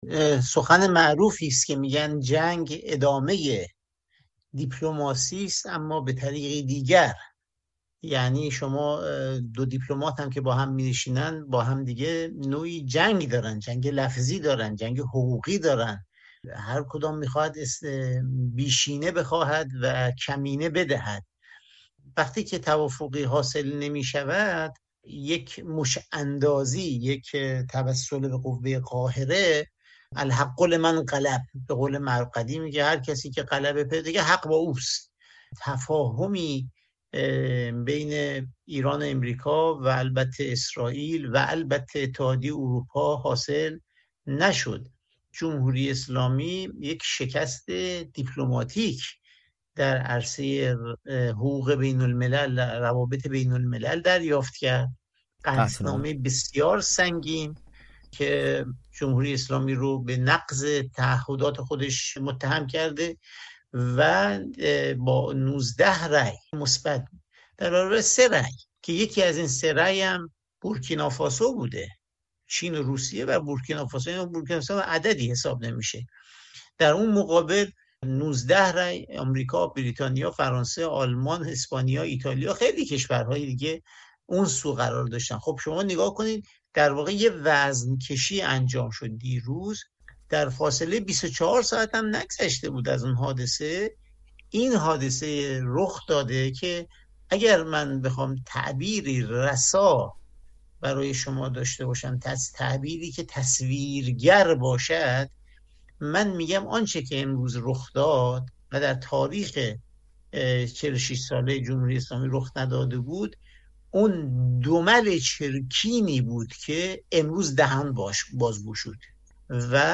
در پی حمله‌های بامداد جمعه اسرائیل به ایران، حسین علیزاده دیپلمات پیشین جمهوری اسلامی در گفت‌وگو با رادیو فردا بخت قابل ملاحظه‌ای برای دیپلماسی در شرایط کنونی قائل نیست.